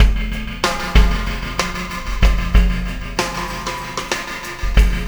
Pulsar Beat 16.wav